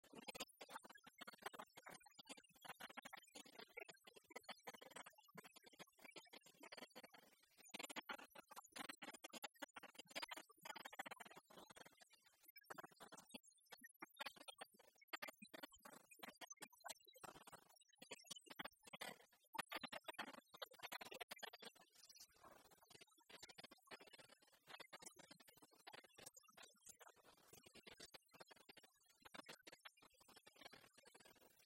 Chansons en dansant
Pièce musicale inédite